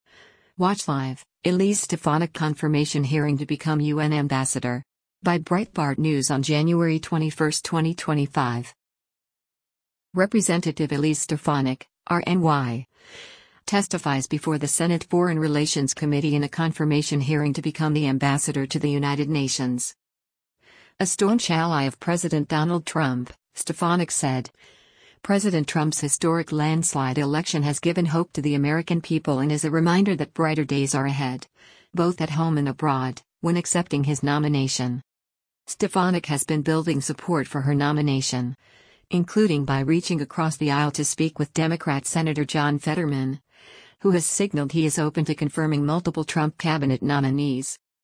Rep. Elise Stefanik (R-NY) testifies before the Senate Foreign Relations Committee in a confirmation hearing to become the ambassador to the United Nations.